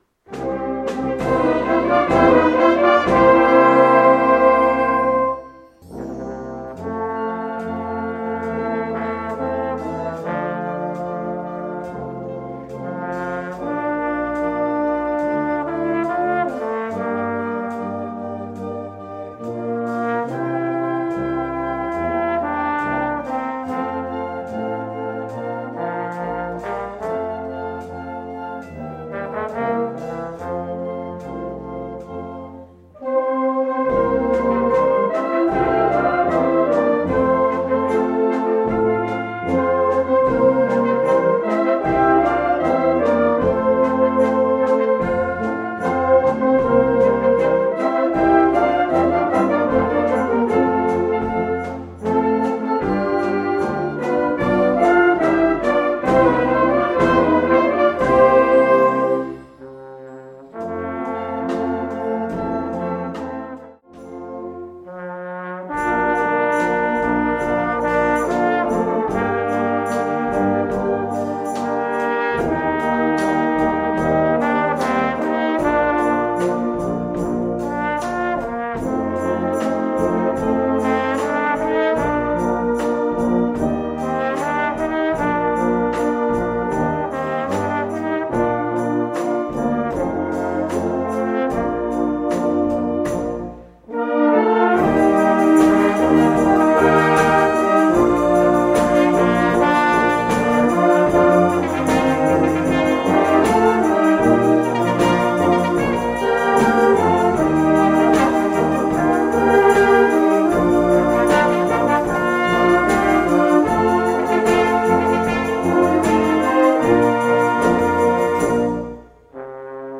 Slow-Beat Solo für Posaune